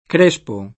crespo [kr%Spo] agg.